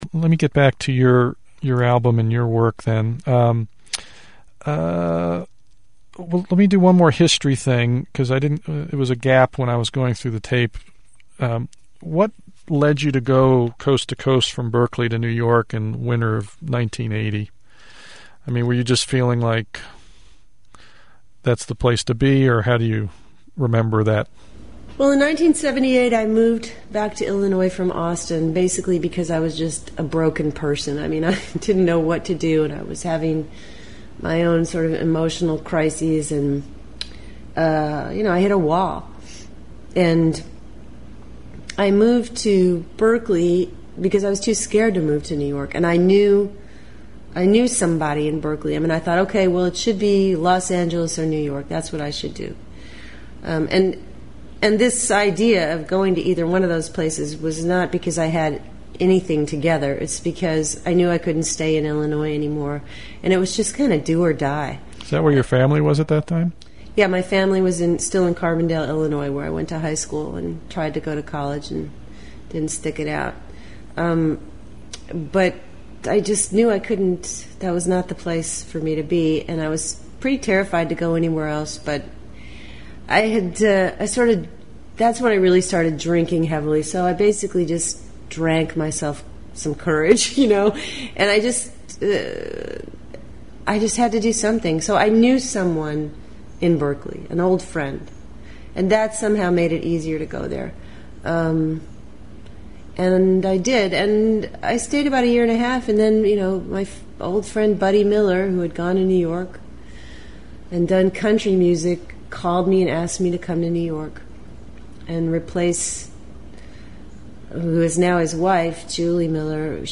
EXTENDED INTERVIEWS WARNING